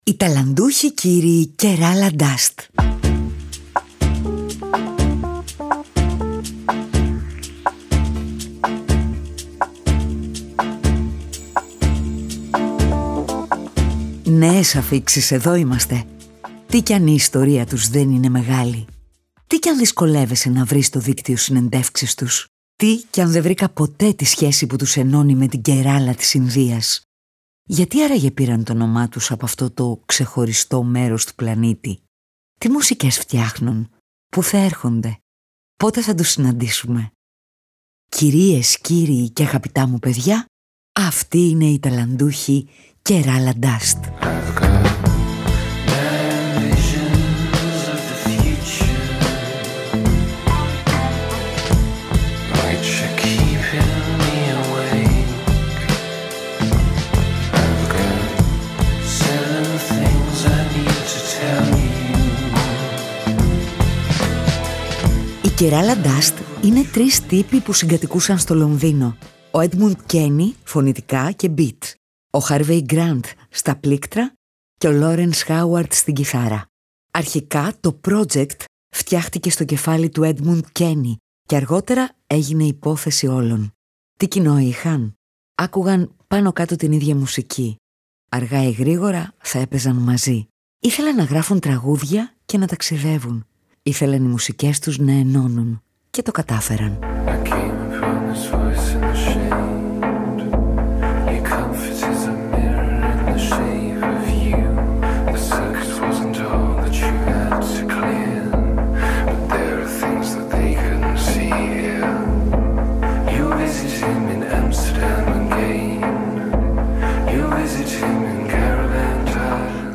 Η μουσική τους είναι σα να παραπατάει ο Τόμ Γουέιτς σ’ έναν electro ιστό αράχνης. Σα να γλιστράει το αμερικάνικο blues σε μια σύγχρονη τσουλήθρα από beat. Σα ν’ ανοίξαμε τη σκονισμένη βαλίτσα και να ξεχύθηκε η ψυχεδέλεια των 70ς αλλά μάντεψε είμαστε στο 2023. Σα να κόλλησε η βελόνα σ’ έναν υπνωτιστικό ήχο που σε κάνει να λιώνεις και να ταξιδεύεις.